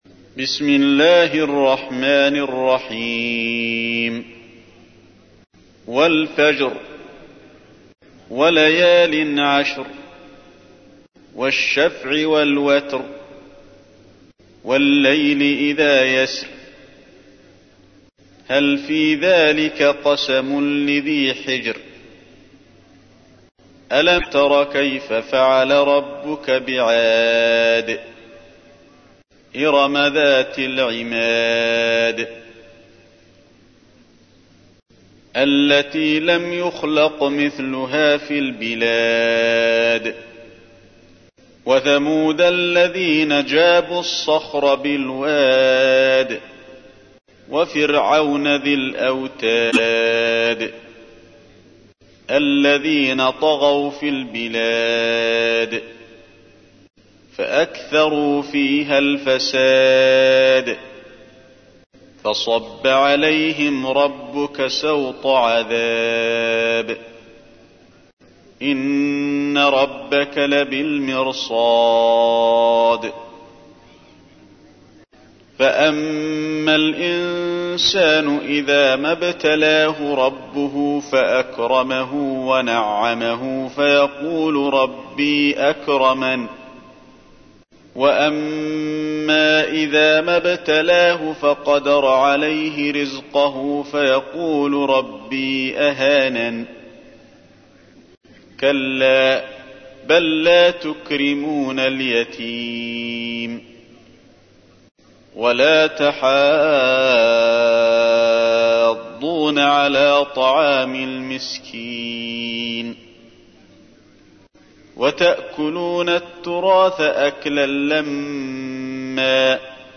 تحميل : 89. سورة الفجر / القارئ علي الحذيفي / القرآن الكريم / موقع يا حسين